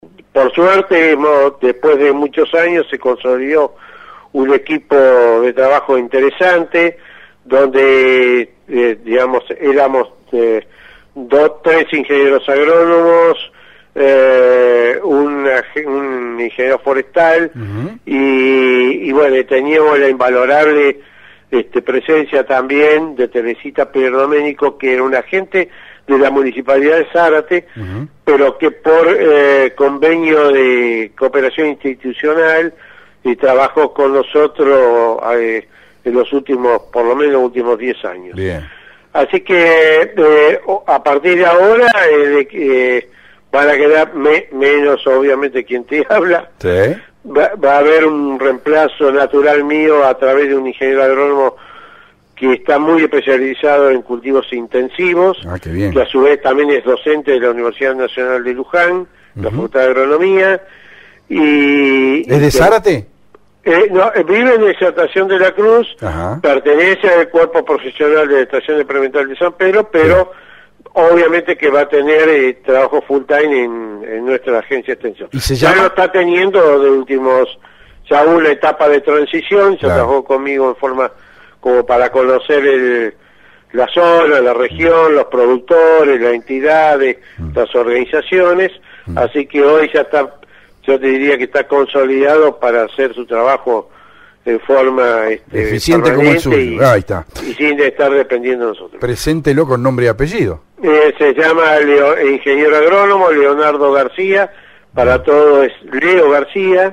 EMOTIVA ENTREVISTA.